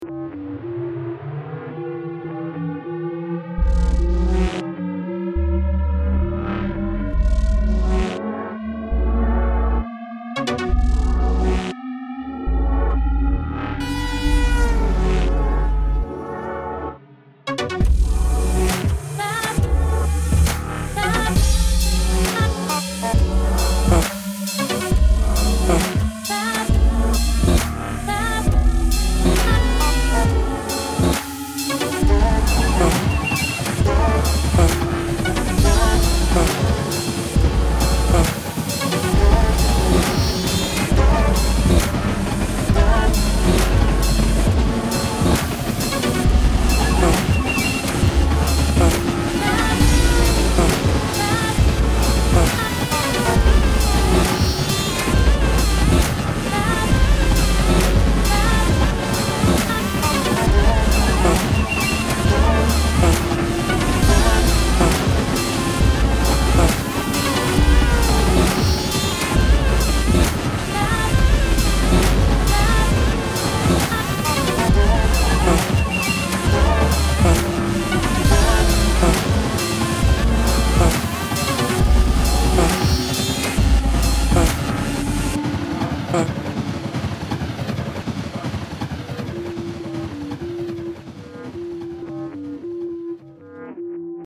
Upon entering the exhibition, the viewer will first notice the fort and the colorful chaos around it, as well as the chaotic music.
Personal Space (played outside the fort)